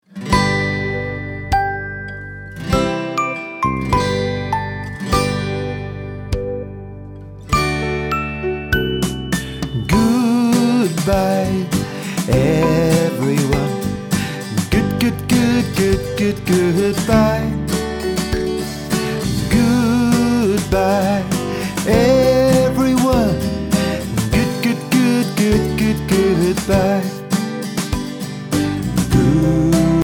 (farewell song)